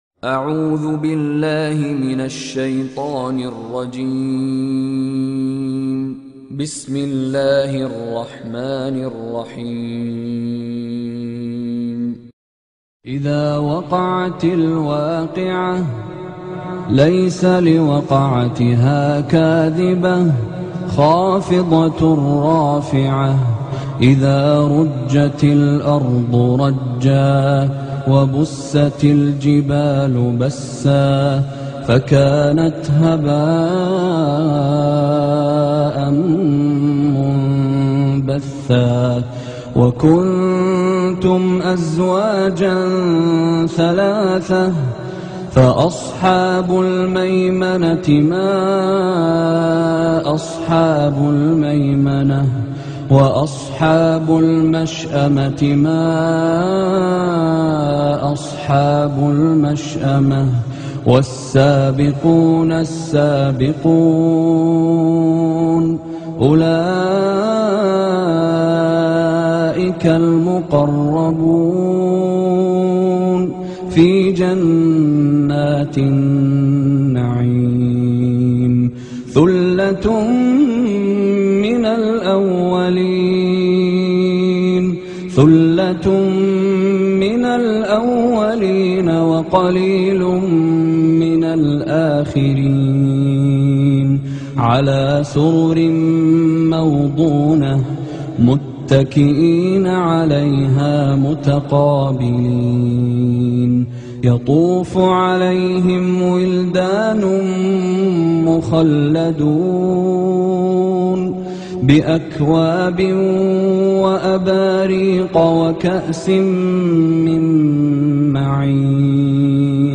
Surah Waqiah Beautiful Recitation MP3 Download By Sheikh Mishary Rashid in best audio quality.